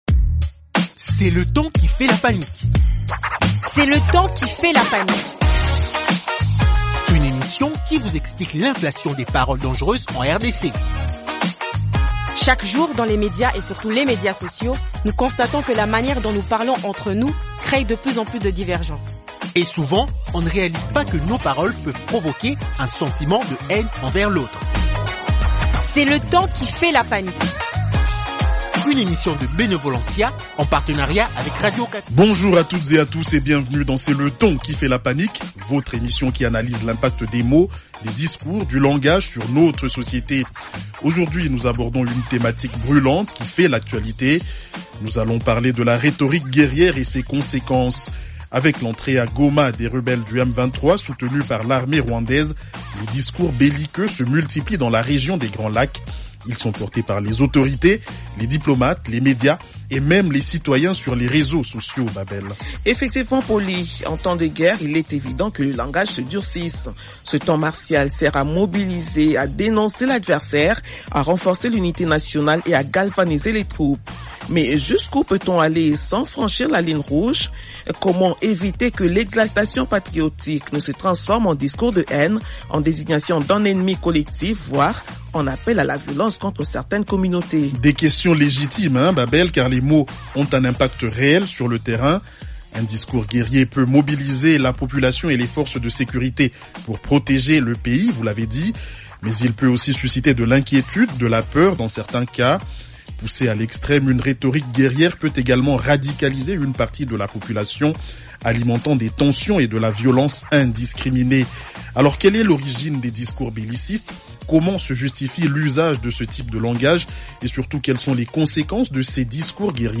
Pour en parler, deux invités :